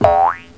BOING1.mp3